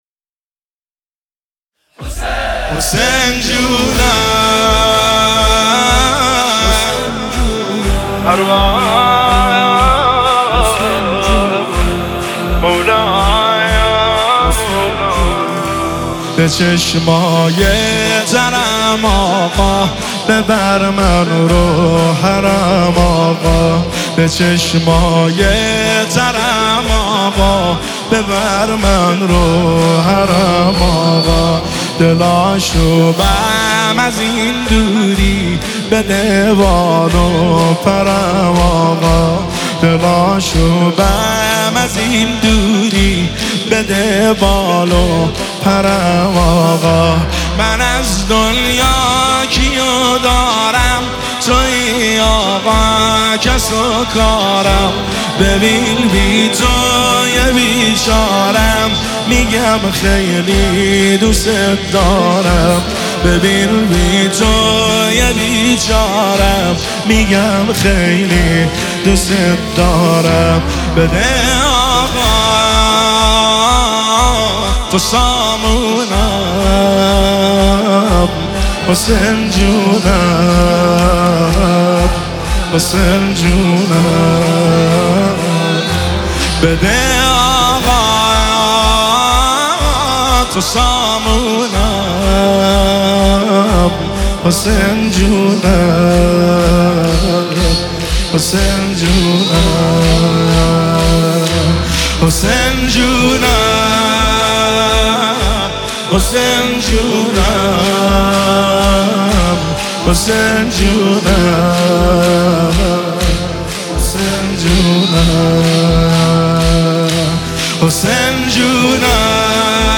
نماهنگ احساسی و زیبای